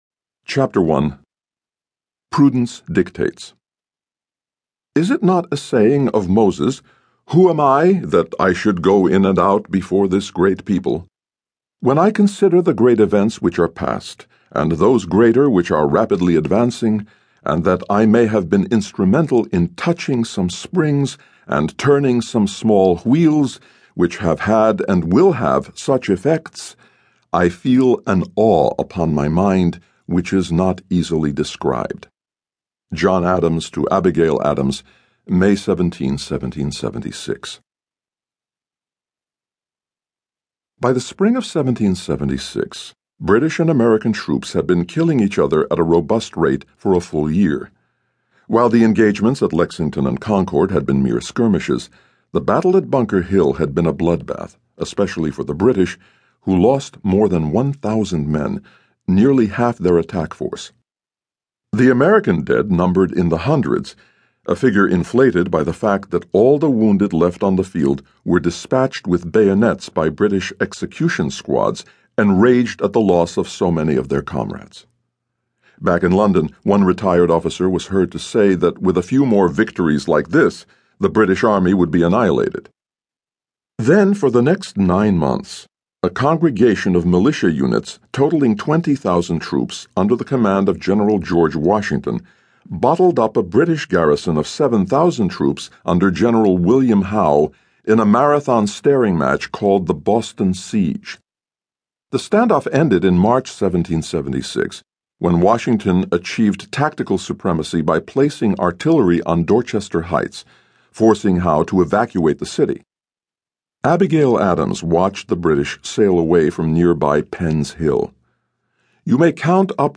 revolutionary-summer-Audiobooks-1.mp3